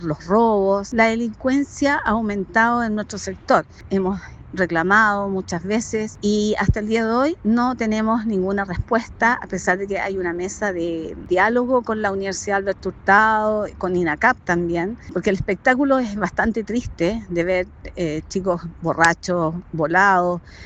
Una vecina entregó su testimonio, declarando que la situación no ha cambiado, pese a que hay mesa de diálogo con la Universidad Alberto Hurtado y el INACAP.